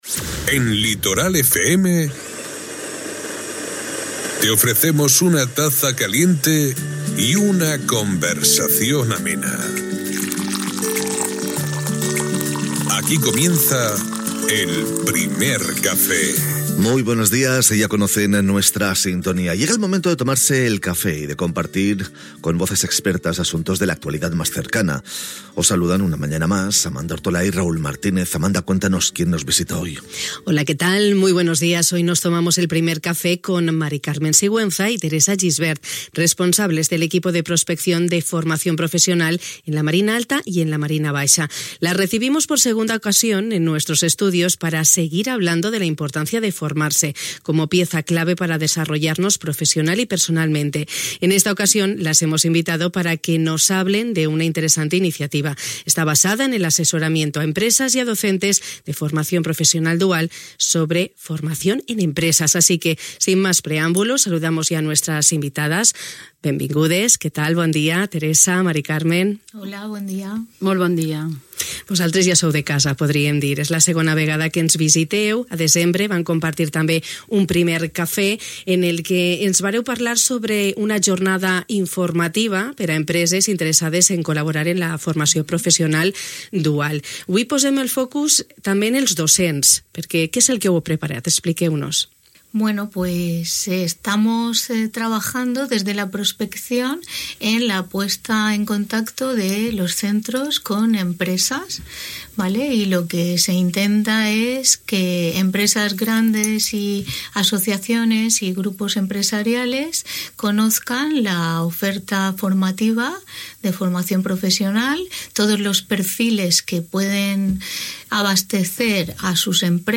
Les hem rebudes per segona ocasió als nostres estudis per a continuar parlant de la importància de formar-se, com a peça clau per desenvolupar-nos professionalment i personalment.